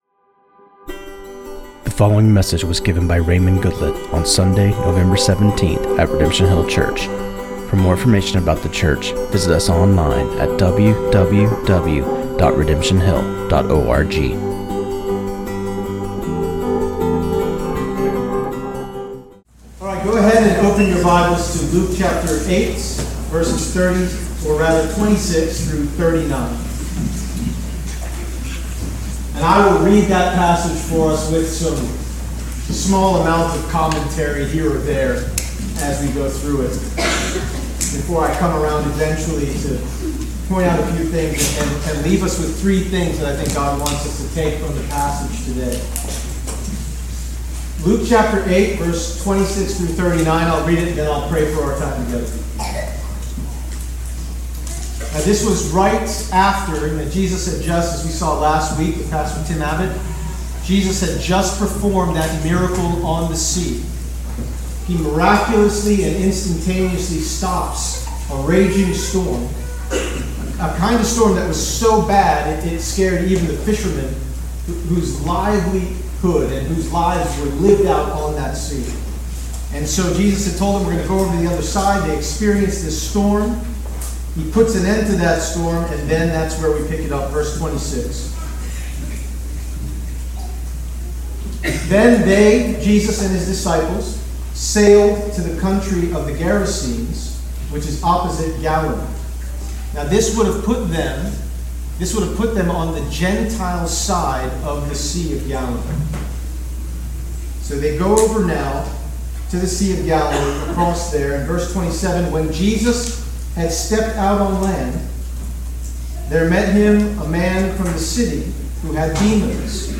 This sermon on Luke 8:26-39